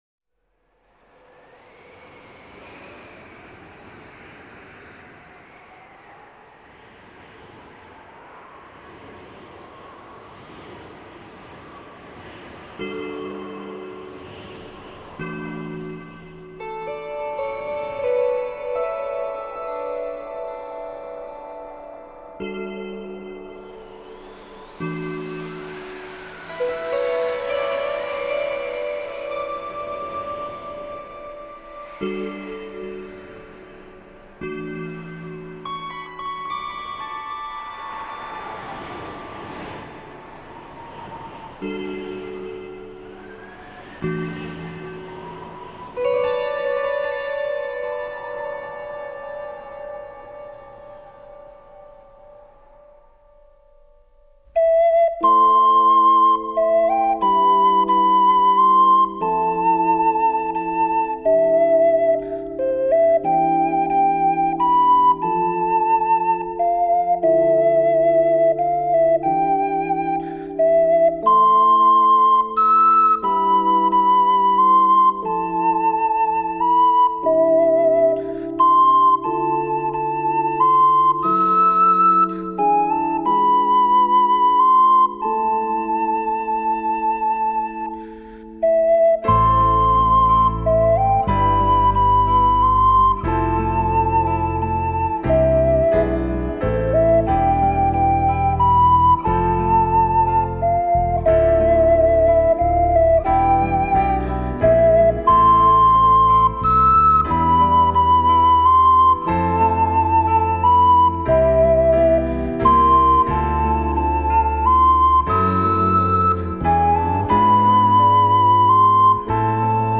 Genre: New Age.